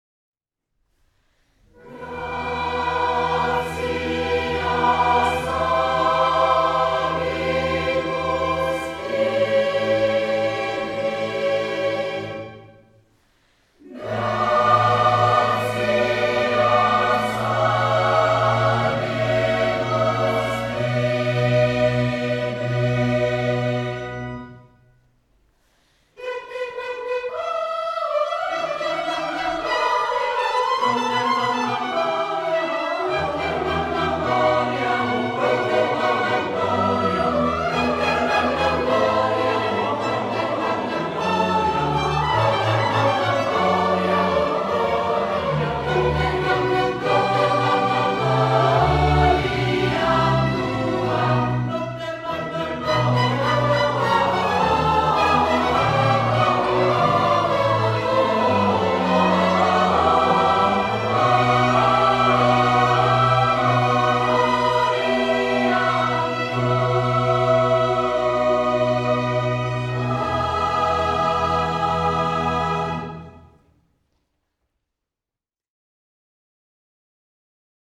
SMÍŠENÝ PĚVECKÝ SBOR CANTARE - Repertoár - Cantare - Klasická hudba
Klasická hudba